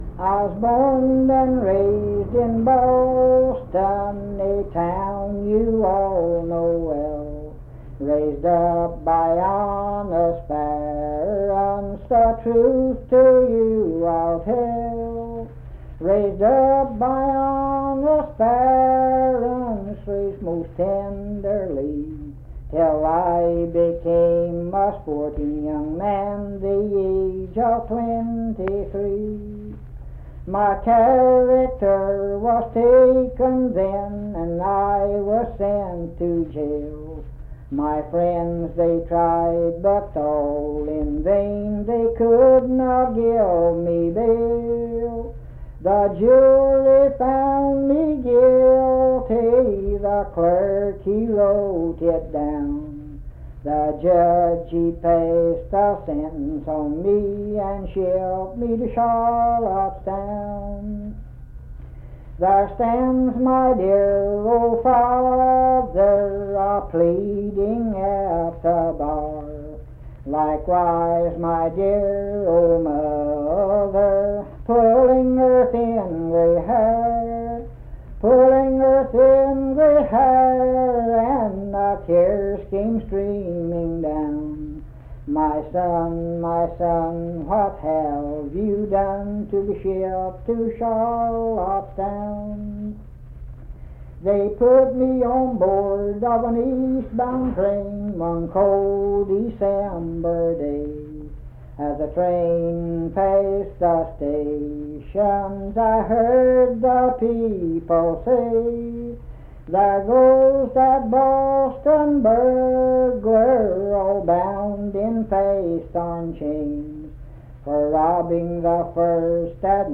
Unaccompanied vocal music
Verse-refrain 6(8).
Performed in Naoma, Raleigh County, WV.
Voice (sung)